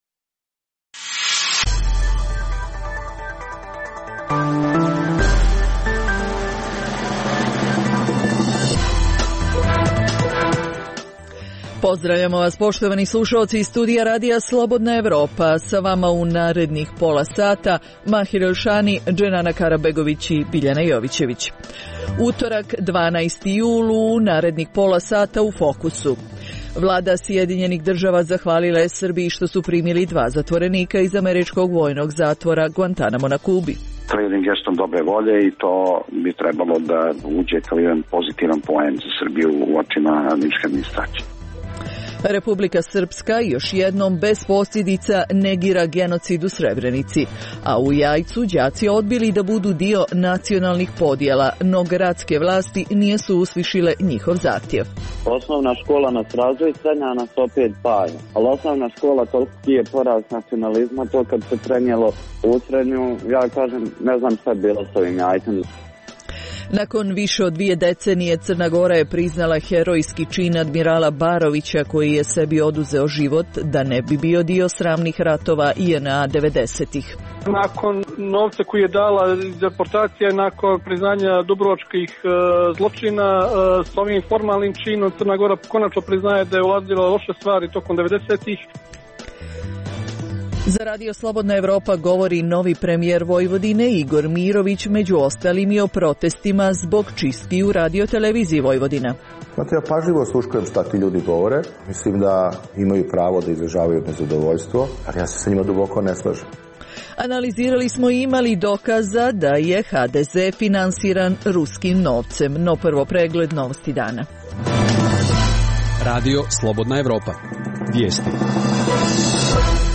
- Za RSE govori novi premijer Vojvodine Igor Mirović, među ostalim i o protestima zbog čistki u RTV. - Analiziramo ima li dokaza da je HDZ finansiran ruskim novcem.